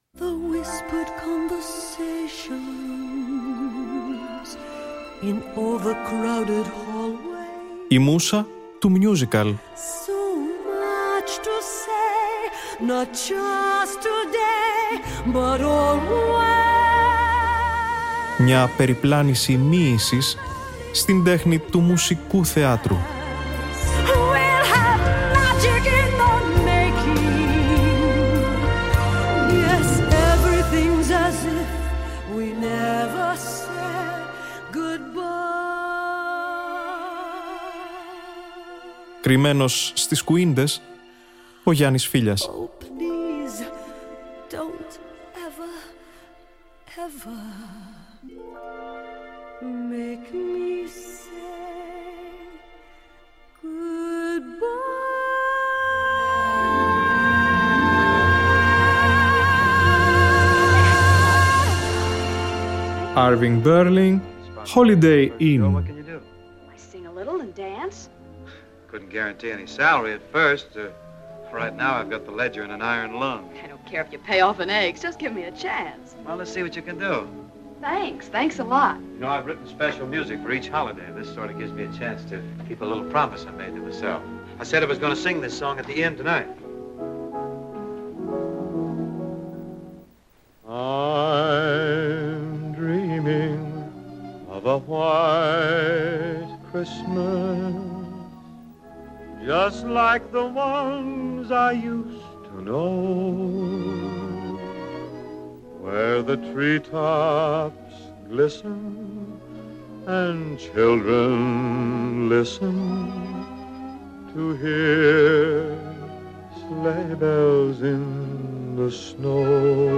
Αυτή την Τετάρτη 13 Δεκεμβρίου, από την ηχογράφηση του 2016 με το original Broadway cast, στη «Μούσα του Musical».